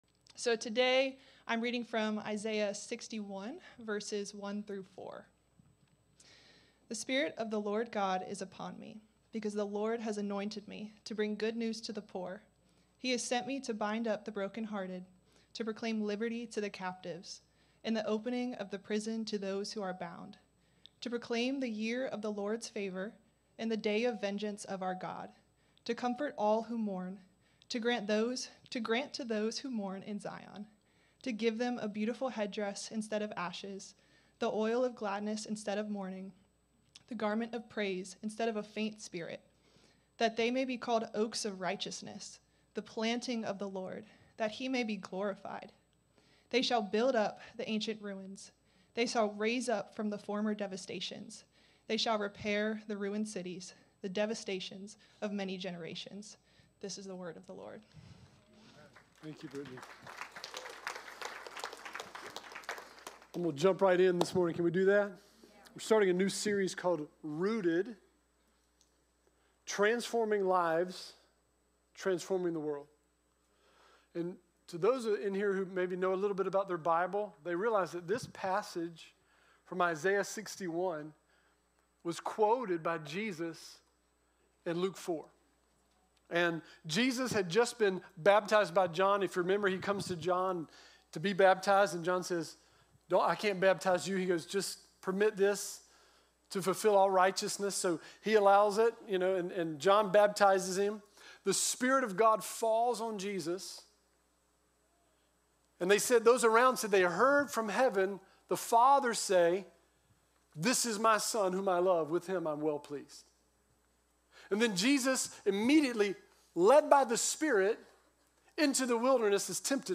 Service Type: Sunday 10am